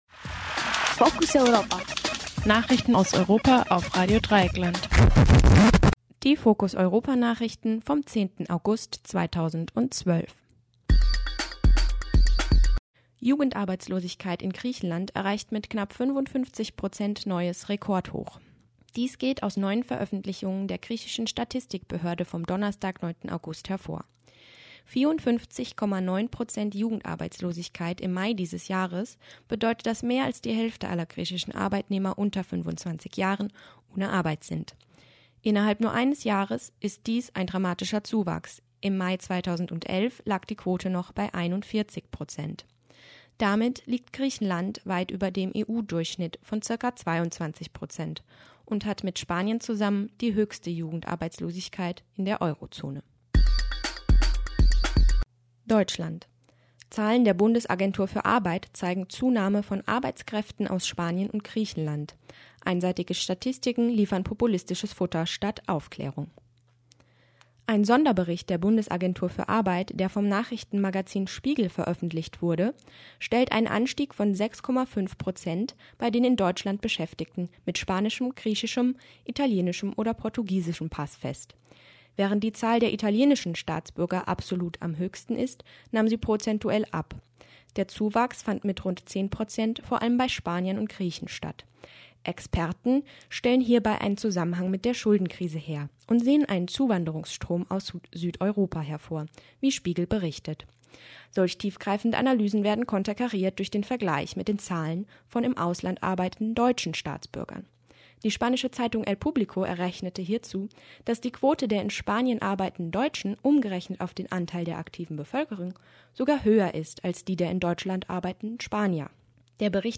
08.05.2014 O-Ton +++++++++++++++ Der EU-Kommissionspräsident José Manuel Barroso fühlte sich während seines Besuchs in der Humboldt-Universität zu Berlin sichtlich unwohl aufgrund Zwischenrufen wie "Solidarität!" und "Troika raus!" .